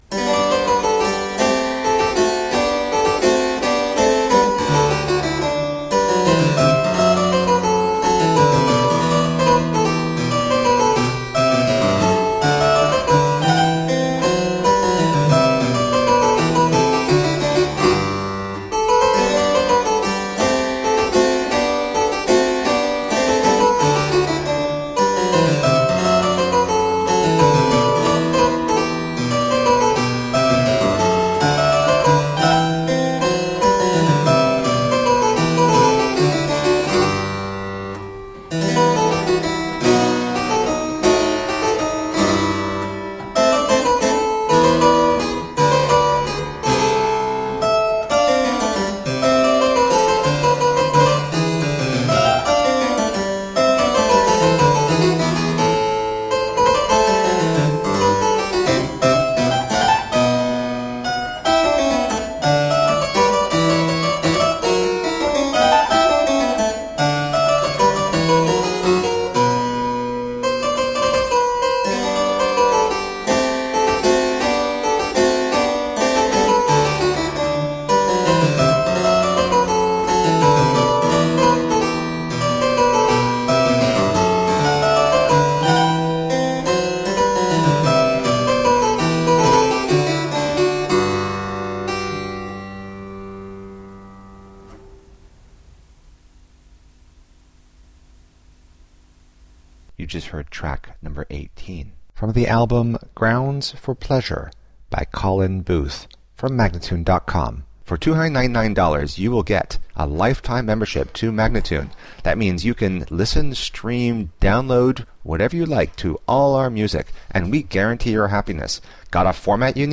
Solo harpsichord music.
Classical, Baroque, Renaissance, Instrumental
Harpsichord